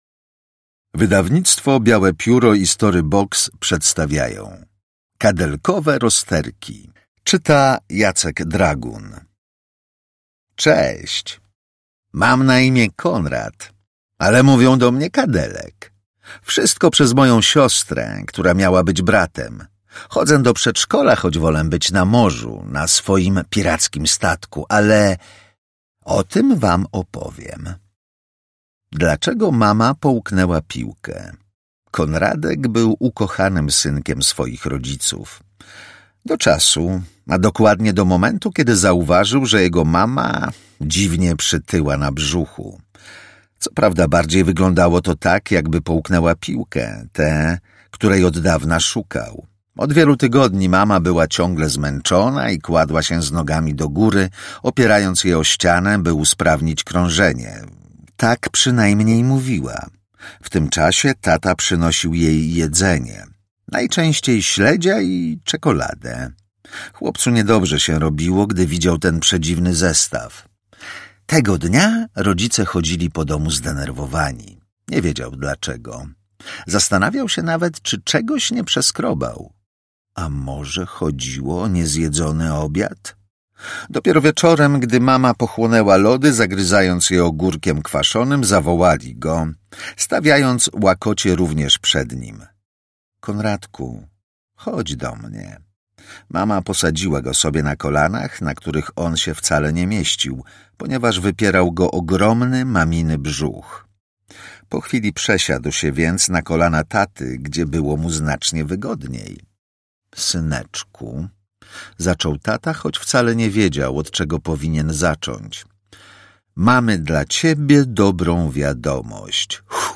Audiobook + książka Kadelkowe rozterki, Magdalena Ludwiczak.